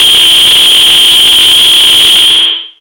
RADIOFX  6-R.wav